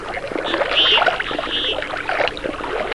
Ce site met à disposition tous ces chants en format wav, je les ais re-transcrits ici en mp3.
Garrot à œil d'or
Bucephala.clangula.mp3